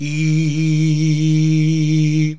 IIIIIIH A#.wav